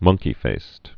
(mŭngkē-fāst)